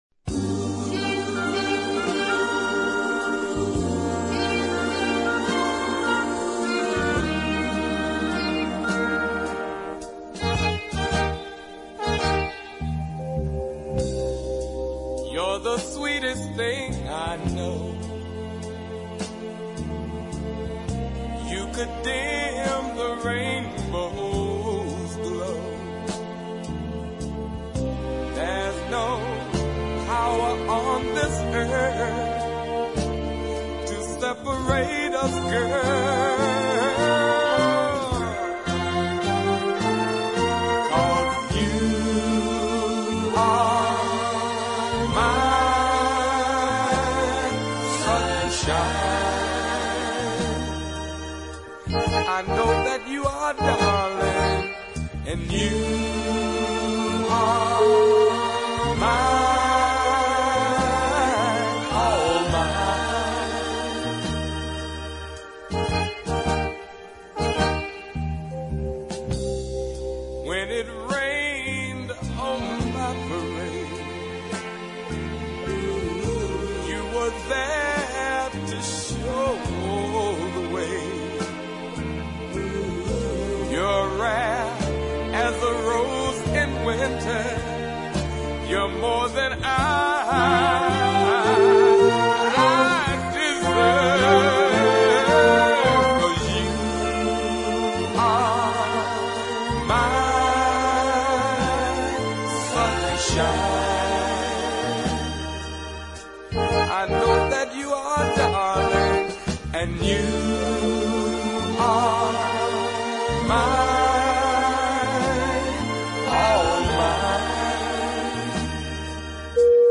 light, expressive tenor vocals
brooding deep version